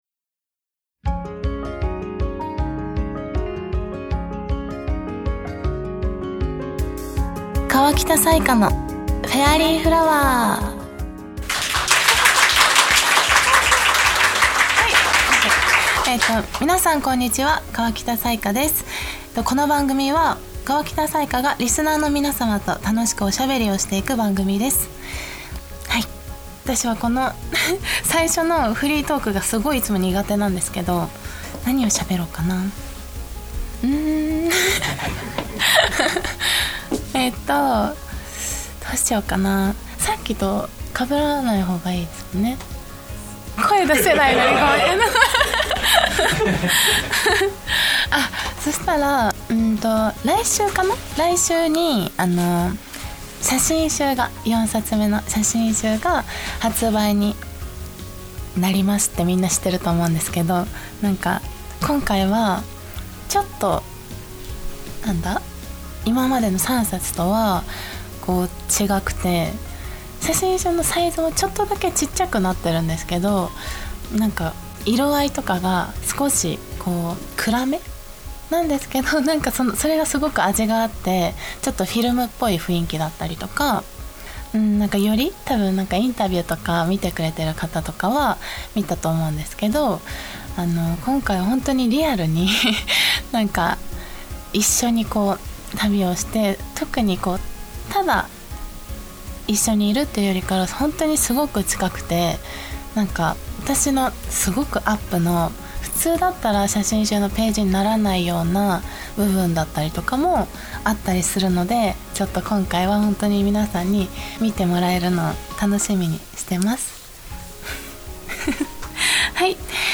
公開収録第二部をお届けです！今回はファッションやネイルなど彩伽さんのこだわりをお話しいただきました♪